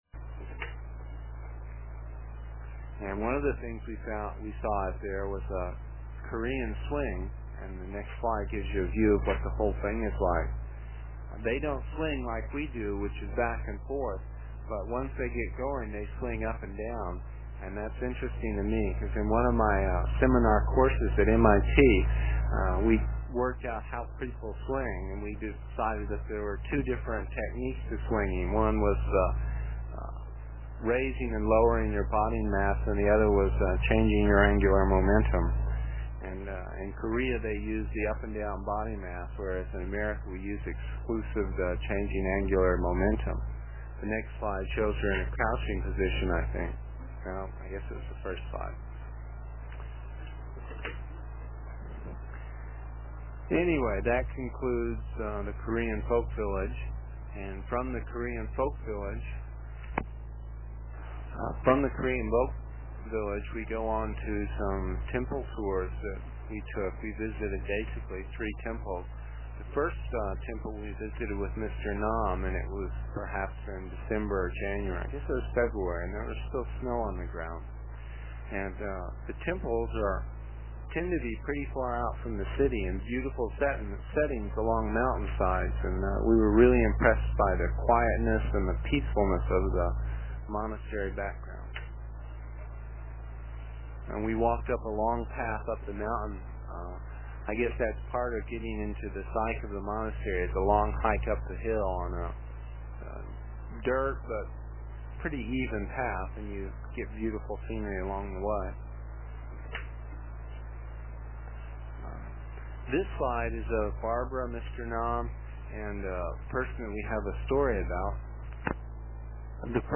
It is from the cassette tapes we made almost thirty years ago. I was pretty long winded (no rehearsals or editting and tapes were cheap) and the section for this page is about six minutes and will take about two minutes to download with a dial up connection.